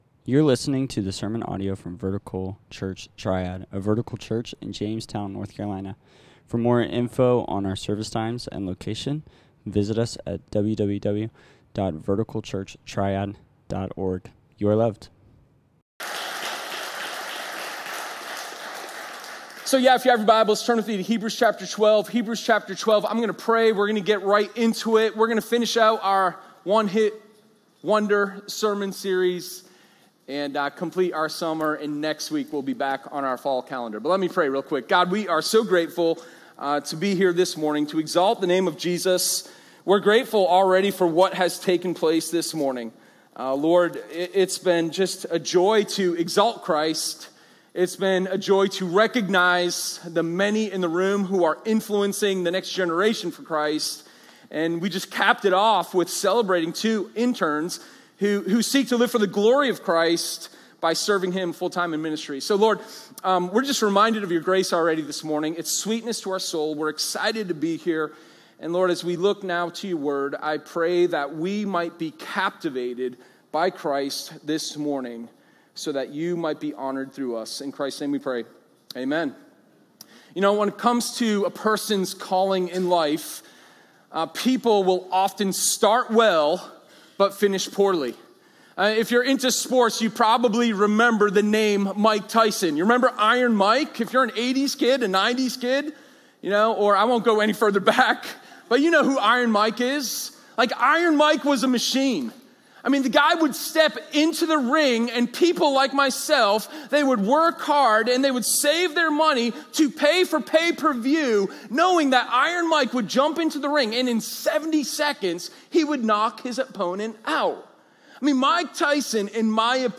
Sermon0807_Finish-Strong.m4a